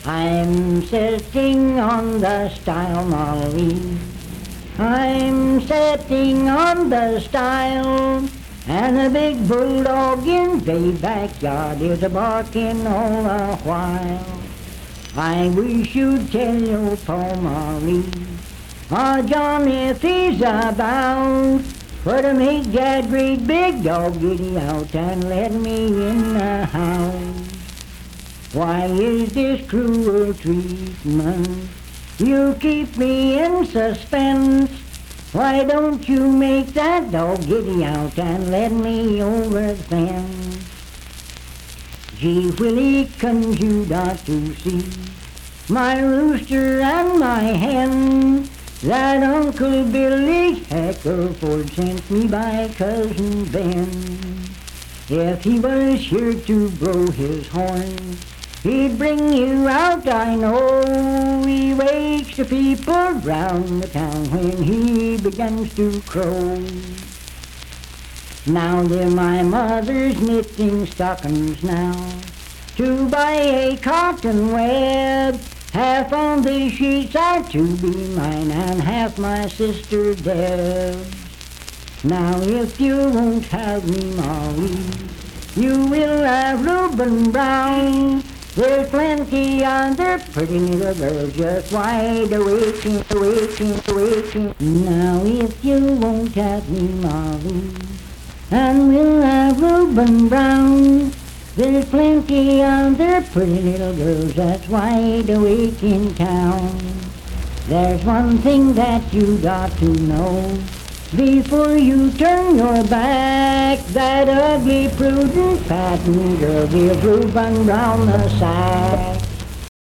Unaccompanied vocal music
Verse-refrain 4(8). Performed in Sandyville, Jackson County, WV.
Voice (sung)